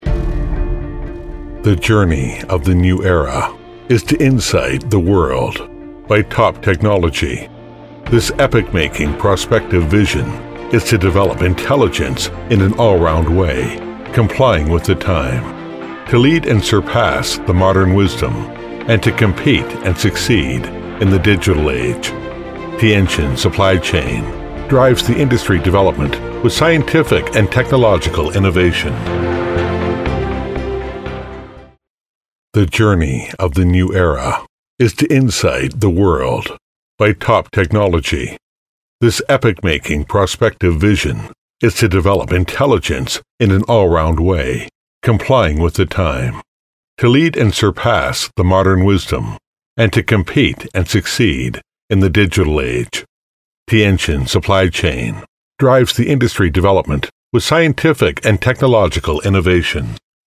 • T110-1 美式英语 男声 天勤 激情激昂|大气浑厚磁性|沉稳|低沉|娓娓道来